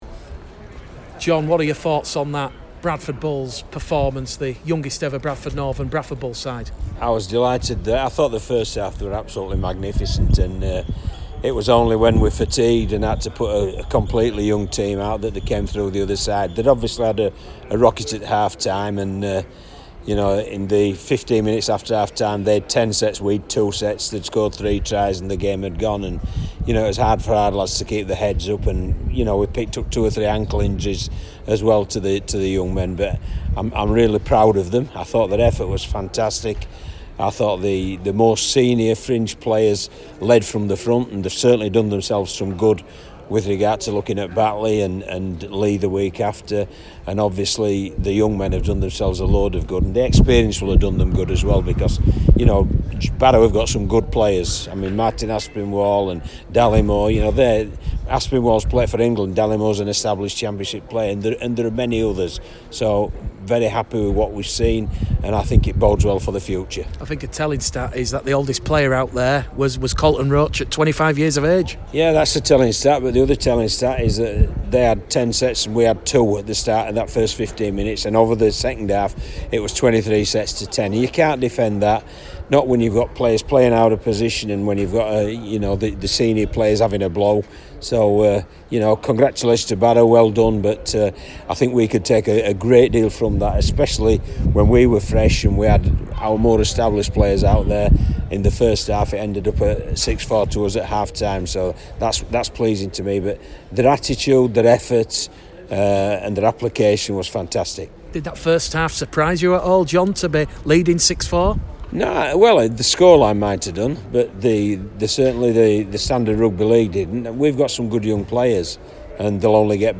Post-Match Interview | Bradford Bulls 6 vs 50 Barrow Raiders